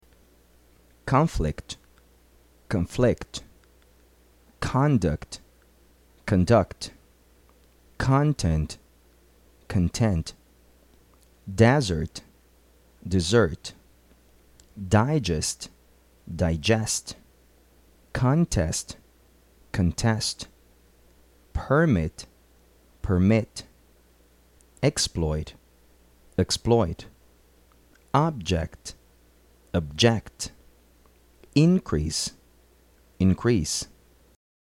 Geralmente nos substantivos a sílaba mais forte é a primeira; nos verbos é a sílaba final.